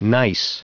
Prononciation du mot gneiss en anglais (fichier audio)
Prononciation du mot : gneiss